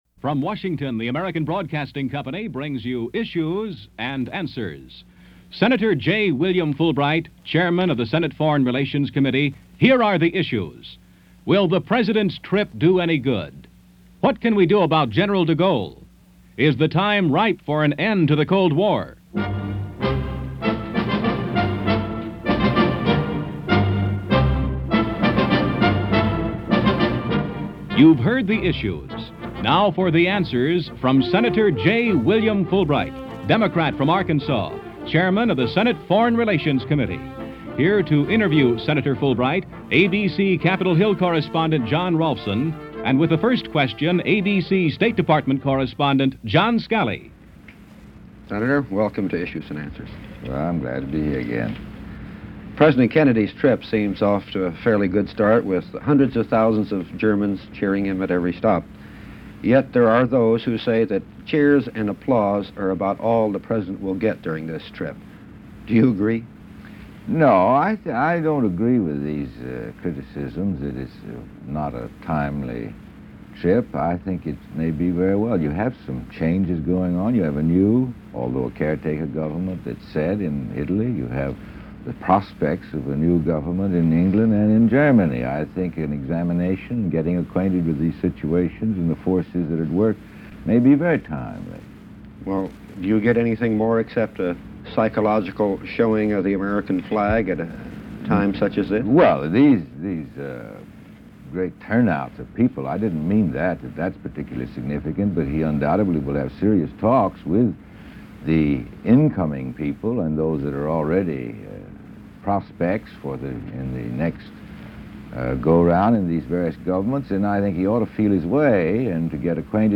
The World According To Fulbright - June 23, 1963 - Interview with Sen. J. William Fulbright - Issues and Answers - ABC Radio
On this installment of ABC Radio’s Issues and Answers, the guest was Senator J. William Fulbright – Chairman of the Senate Foreign Relations Committee. The talk was about our Foreign Policy and what was going on with some of our allies.